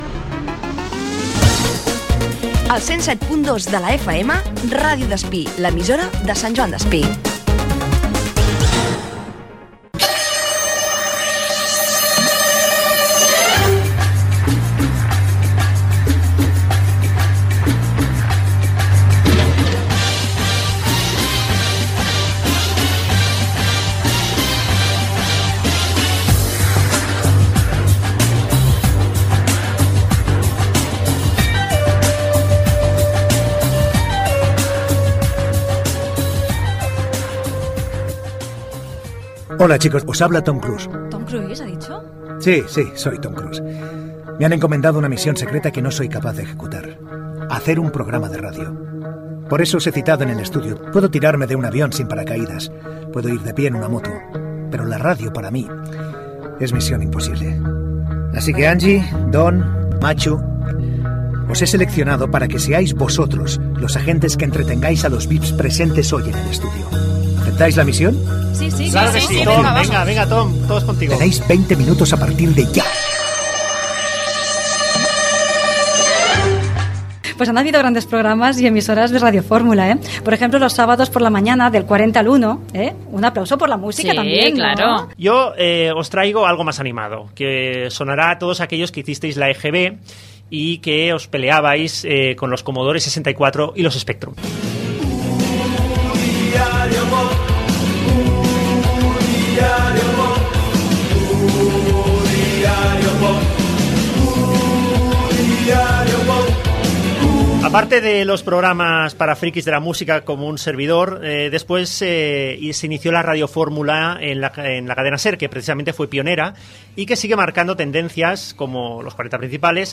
Indicatiu de l'emissora, programa amb motiu del Dia Mundial de la Ràdio 2017. Paraules simulant el que diria Tom Cruise. Menció a alguns programes i ràdios musicals i els diferents formats del mitjà.
Entreteniment